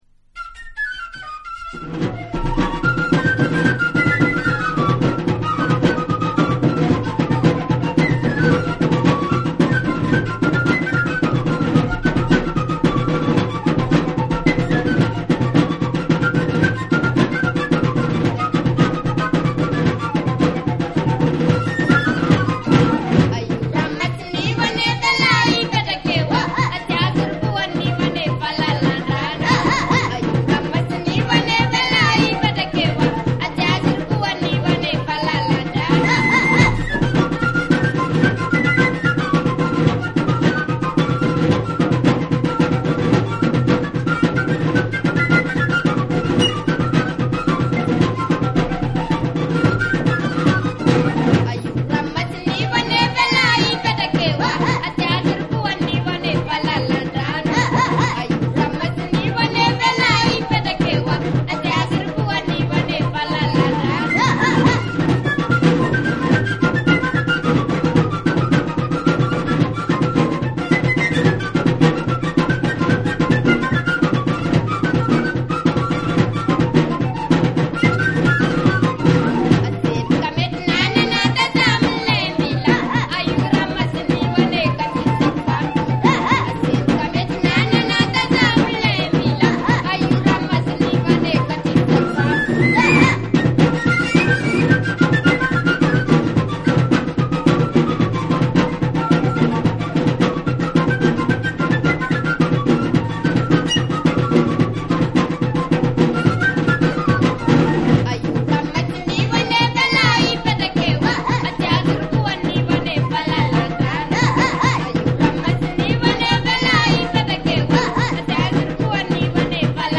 マーチング・ドラムのようなトライバルなビート上でフルートが暴れまくる、牧歌トランシーナンバー
WORLD / AFRICA